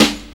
12 SNARE.wav